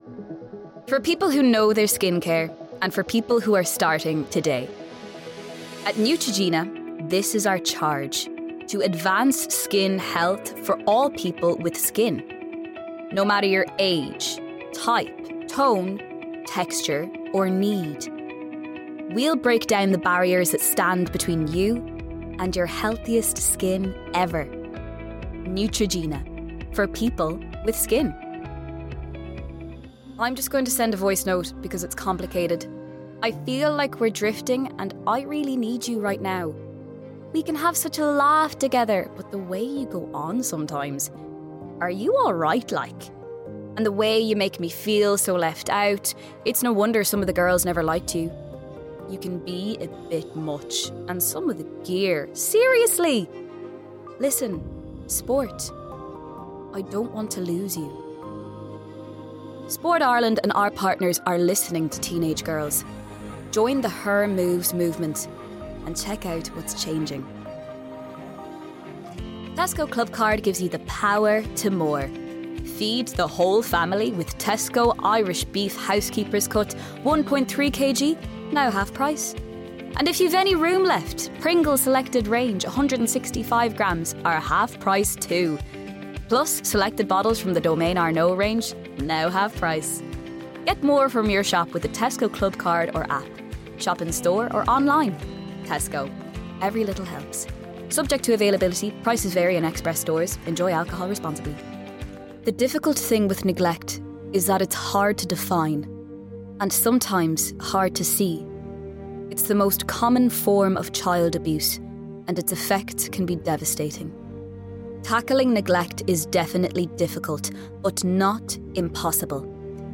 Irish voice over artist
female
voice sampler